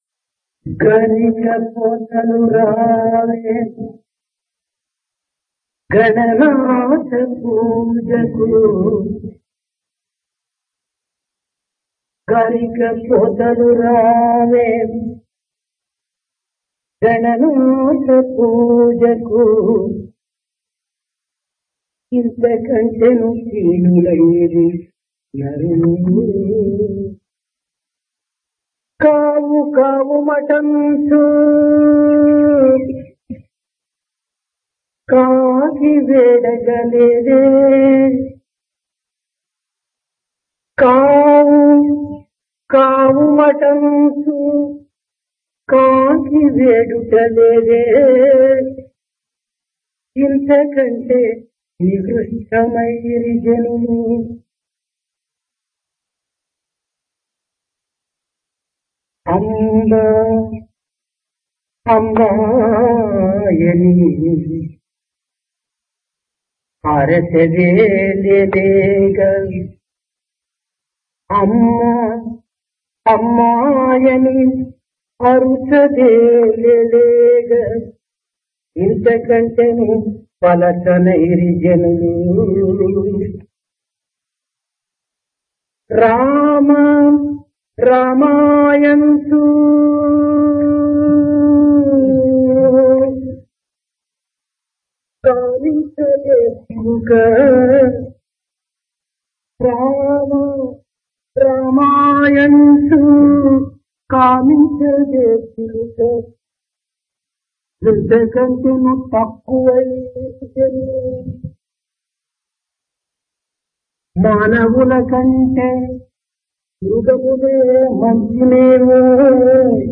Dasara - Divine Discourse | Sri Sathya Sai Speaks
Place Prasanthi Nilayam Occasion Dasara